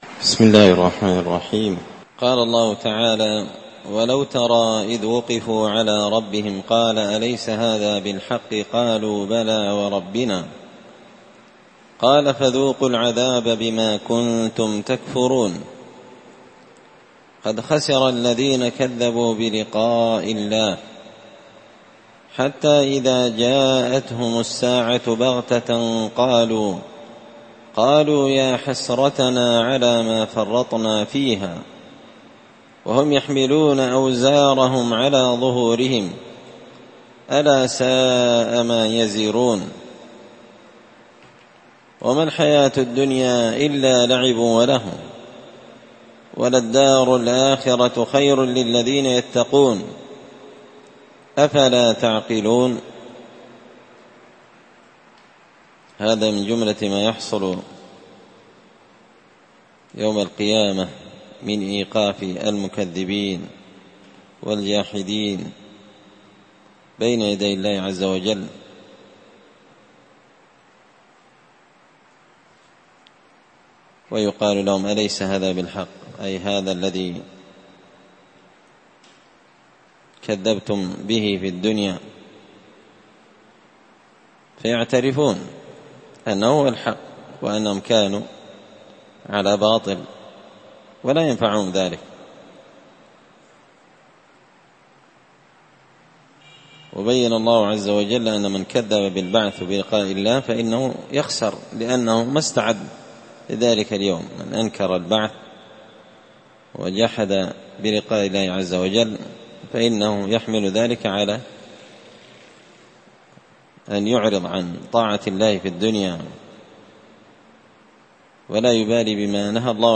مسجد الفرقان قشن_المهرة_اليمن